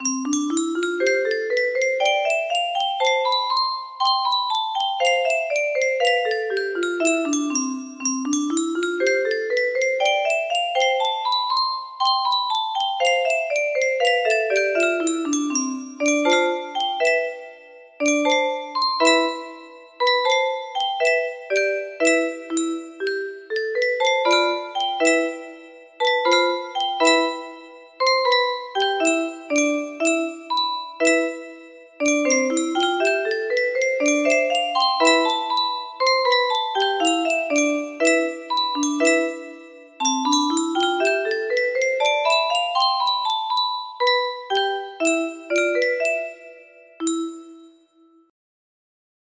Scaling music box melody